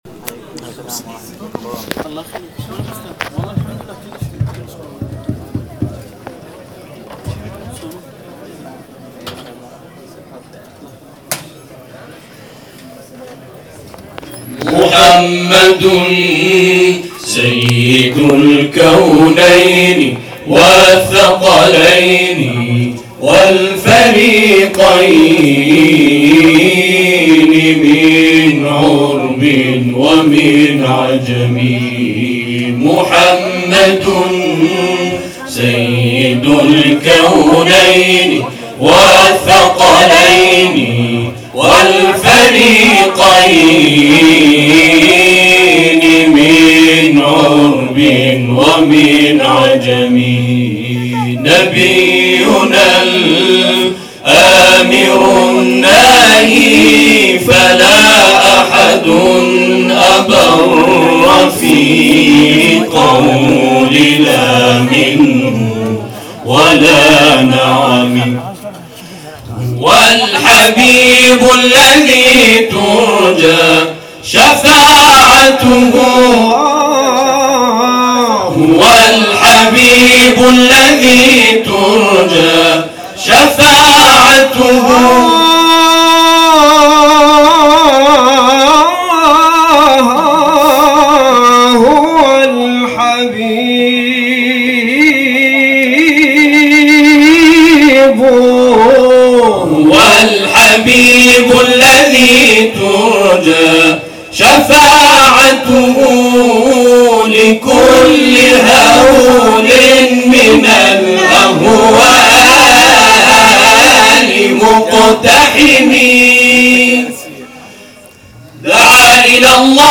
تواشیح
گروه تواشیح میعاد اهواز